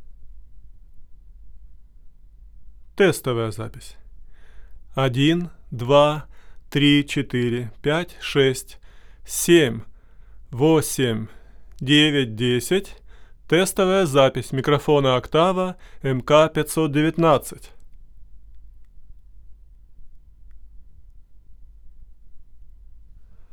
Недавно, сделав запись своего голоса на 519-ю, обнаружил неприлично высокий уровень шума, хотя в предыдущих сессиях недельной давности всё было в норме.
Результат - шумит 519-я. Что посоветуете?
Вложения Noise&Voice Oct.MK-519.wav Noise&Voice Oct.MK-519.wav 2 MB · Просмотры: 128